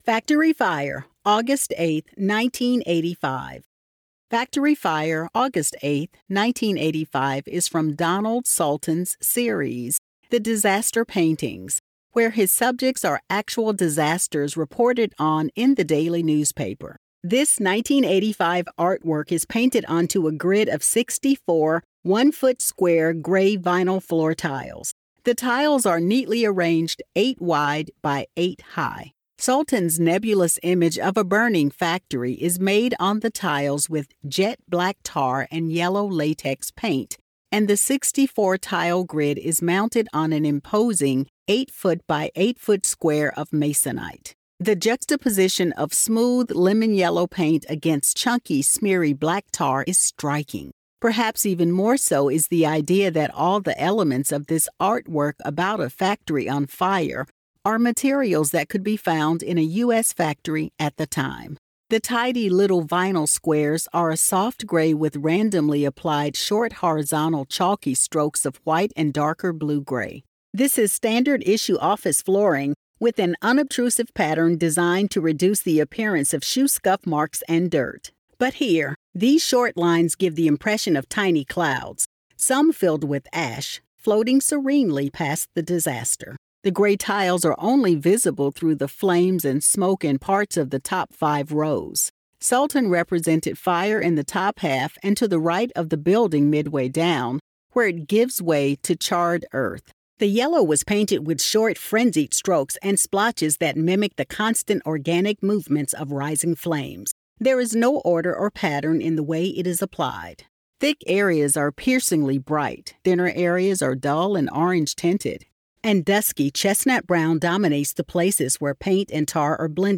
Audio Description (03:02)